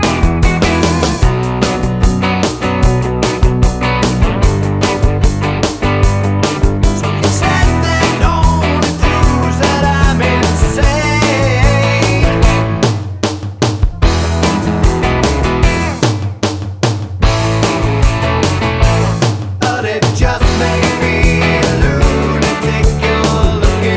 no lead guitar Pop (1980s) 4:02 Buy £1.50